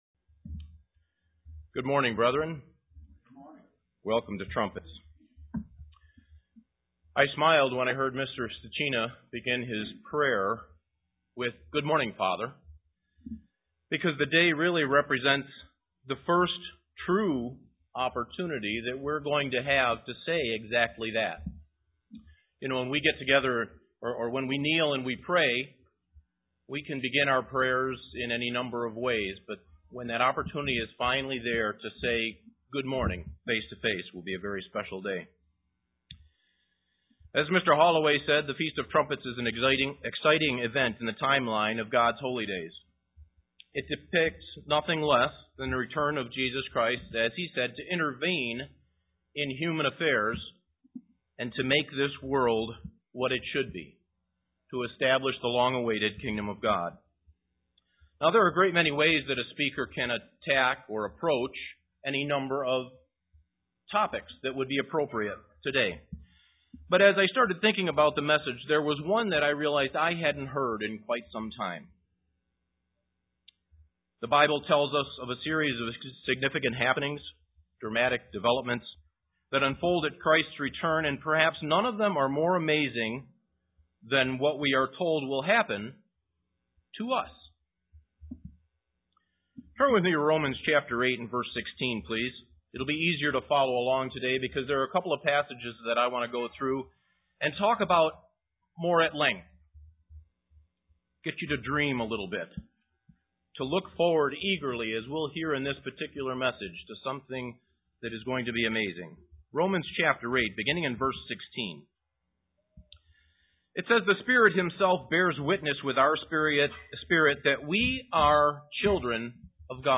AM Sermon What we will be when we are born into the kingdom.